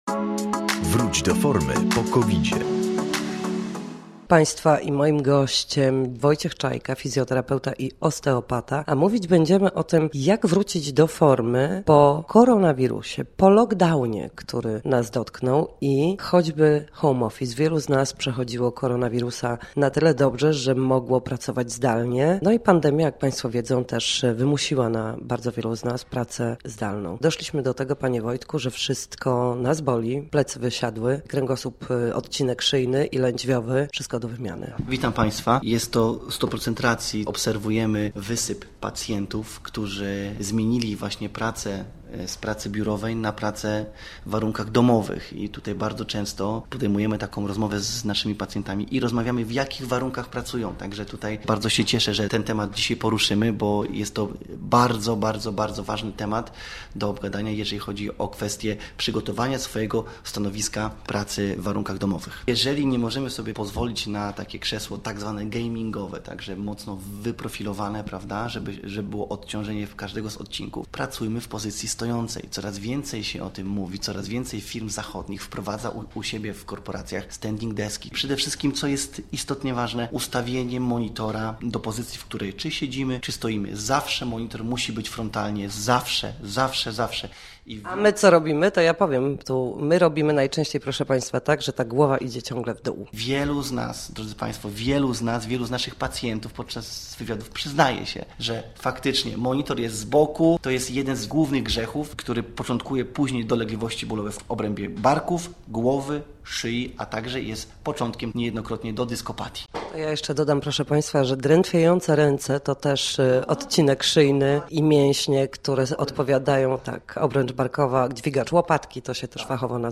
W każdy poniedziałek o godzinie 7:20 na antenie Studia Słupsk przedstawiamy sposoby na powrót do formy po przejściu koronawirusa.